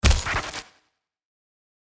CRT_IMPACT.mp3